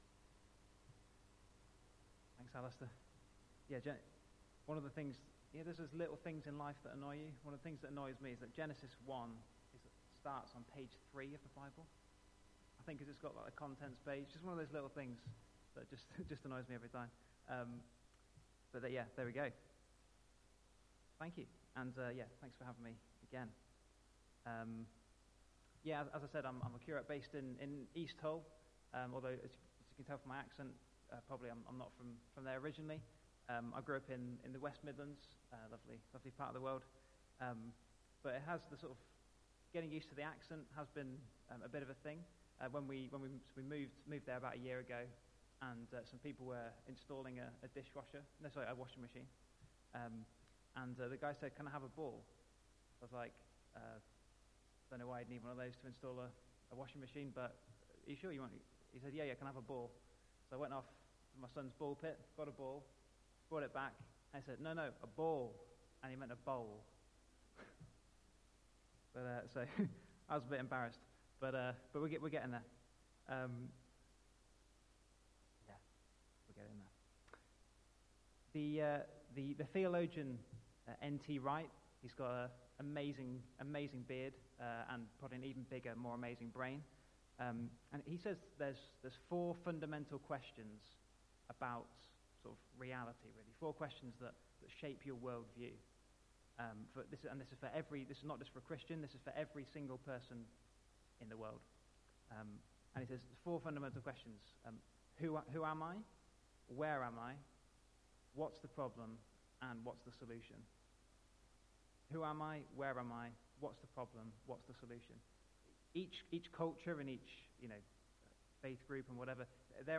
Media Library The Sunday Sermons are generally recorded each week at St Mark's Community Church.
Theme: The Image of God Sermon